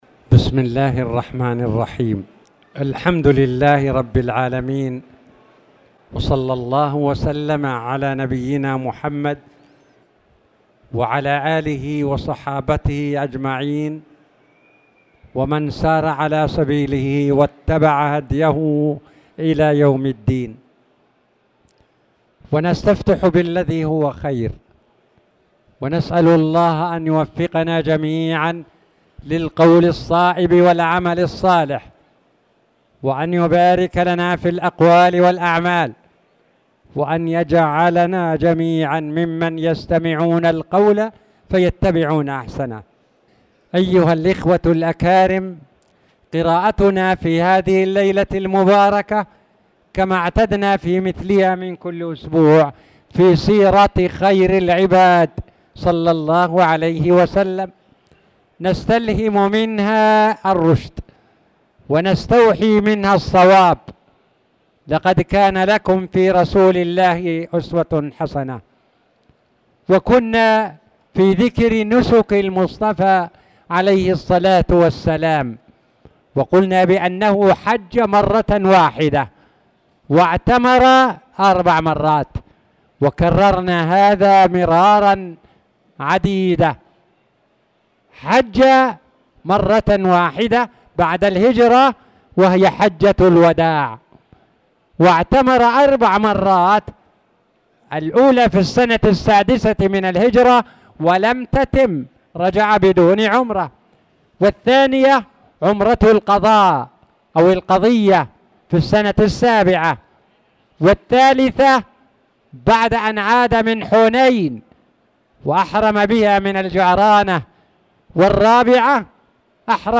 تاريخ النشر ٥ محرم ١٤٣٨ هـ المكان: المسجد الحرام الشيخ